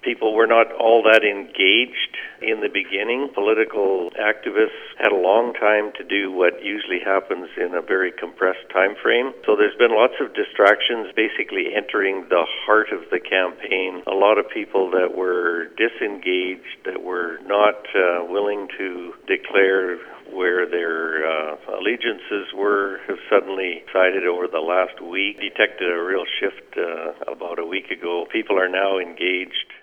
That from Courtenay-Alberni Conservative candidate John Duncan…